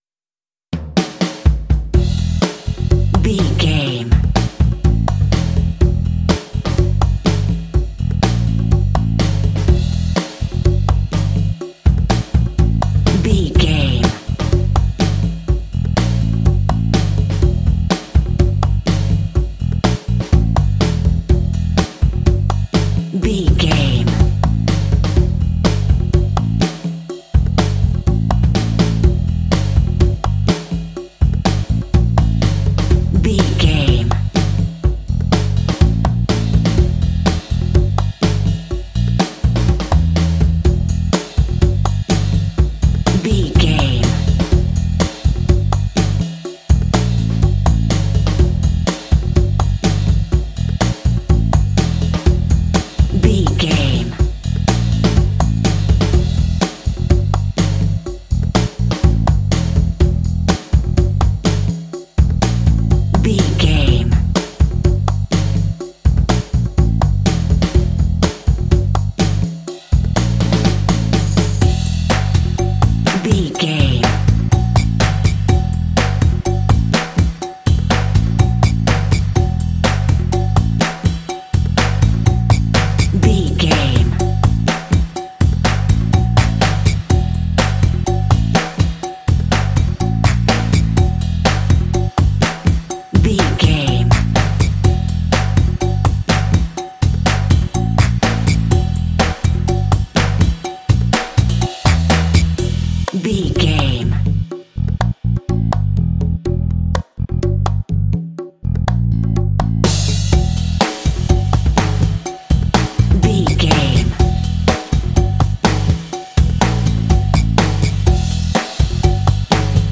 Phrygian
funky
bouncy
groovy
drums
percussion
bass guitar
jazz
Funk